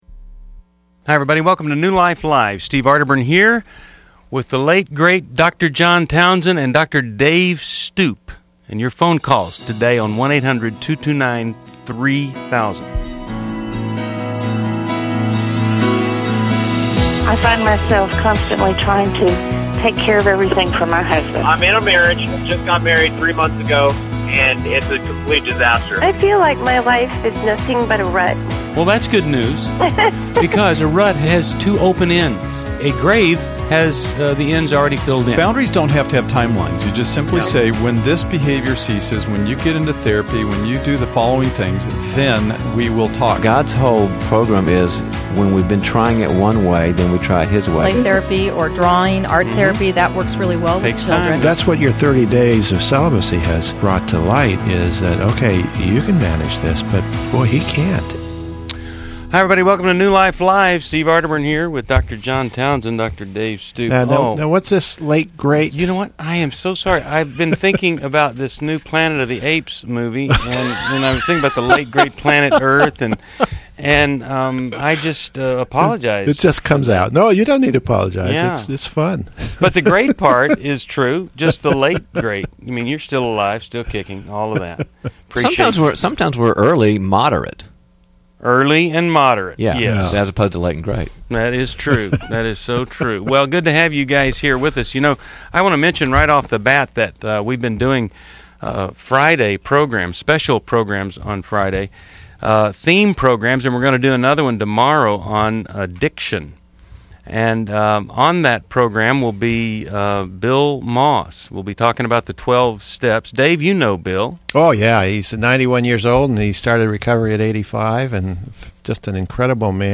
Caller Questions: 1.